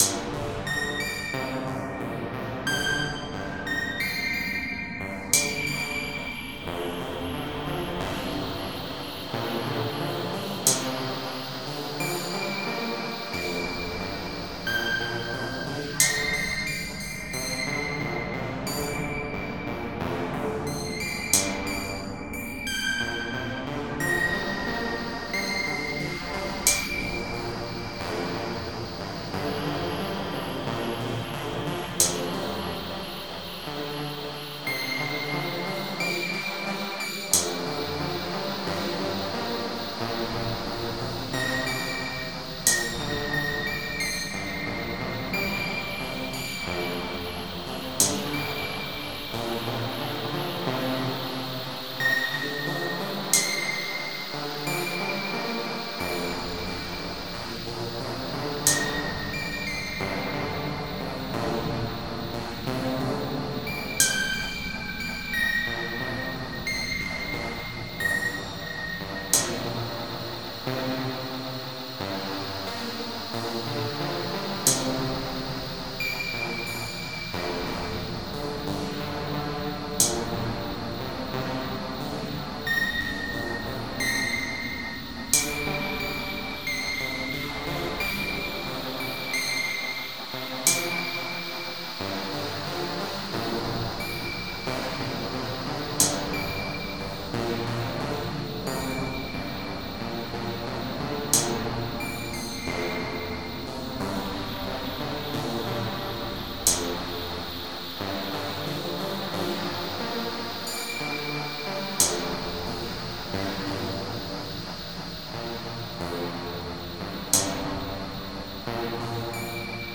Взял генерацию в миди из Aural Fractals и загнал в SunVox. Немного со звуками поигрался. Ткань мелодии в принципе осталась оригинальной.